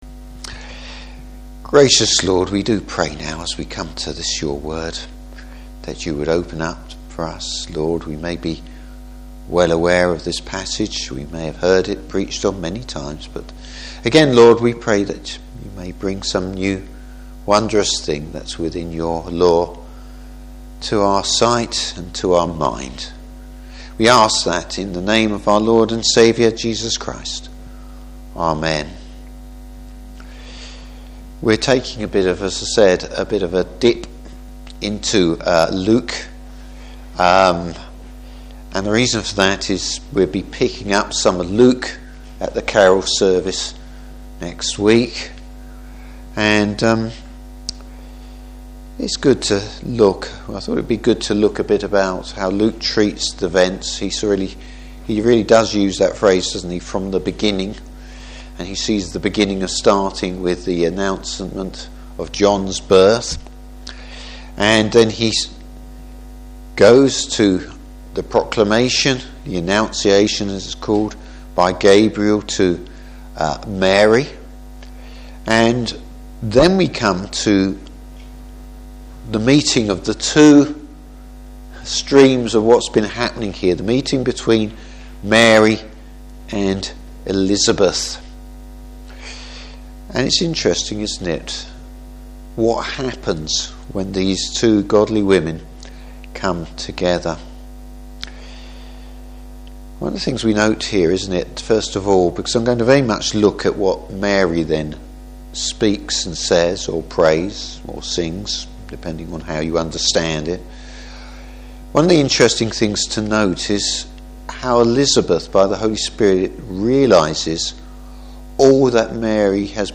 Service Type: Evening Service How Mary’s song of praise draws on scripture.